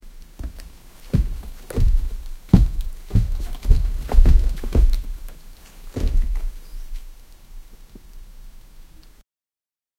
foot trembling
foot-trembling-myovktyv.wav